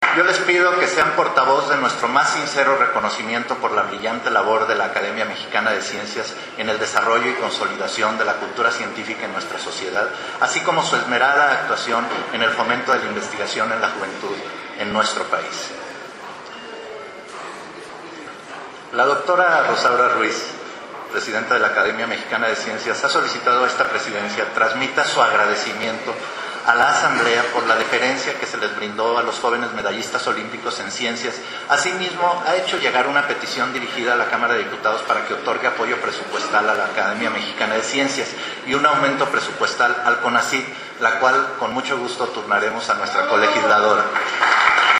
Audio 02: Pleno en la Cámara de Diputados